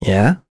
voices / heroes / en
Lucias-vox-Aff2.wav